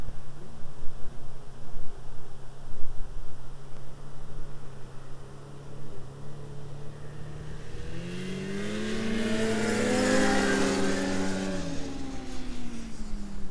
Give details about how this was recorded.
Subjective Noise Event Audio File (WAV)